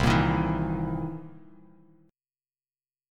C7#9 Chord
Listen to C7#9 strummed